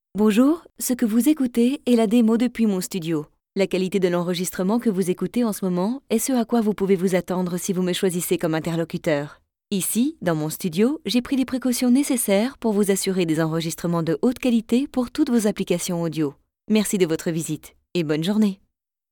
I like to modulate my voice according to the products.
Sprechprobe: Werbung (Muttersprache):
Professional actress without accent for more than 10 years!